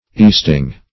easting - definition of easting - synonyms, pronunciation, spelling from Free Dictionary